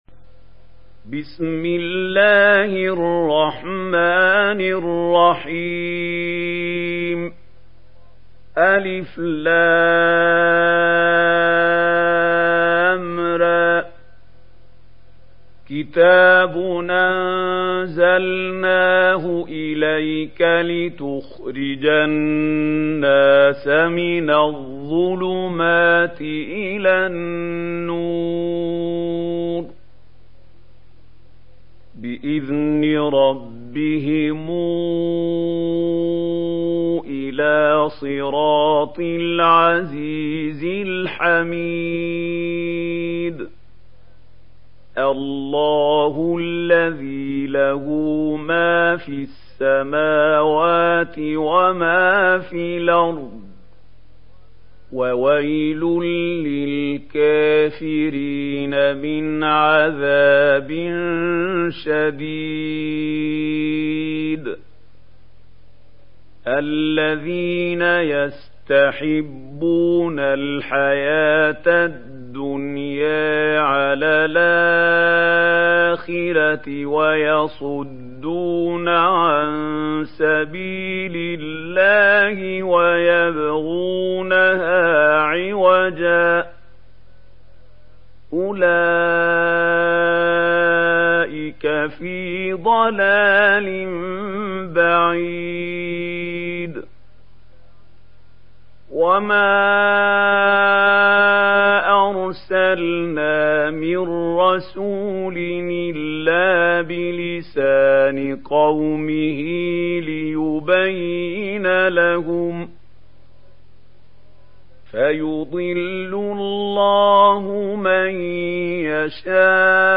Surah Ibrahim Download mp3 Mahmoud Khalil Al Hussary Riwayat Warsh from Nafi, Download Quran and listen mp3 full direct links